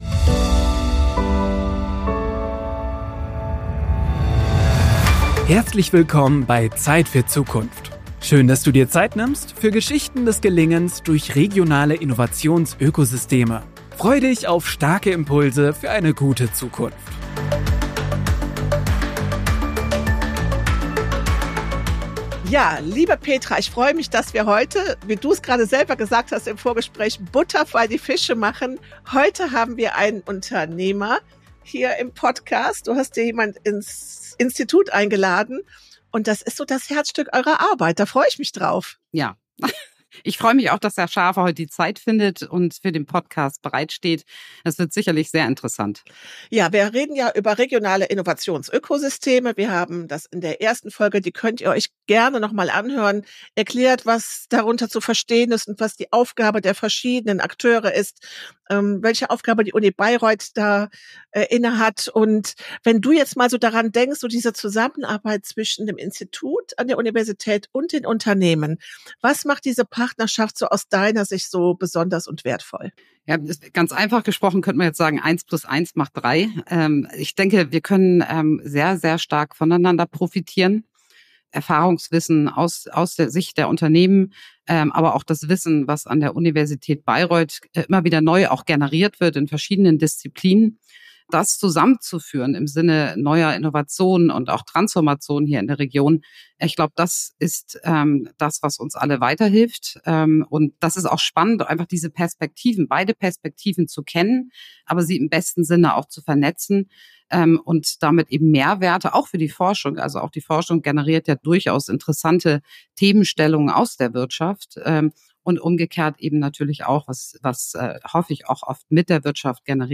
Nähere Infos und Erfahrungen im Talk, hören Sie rein!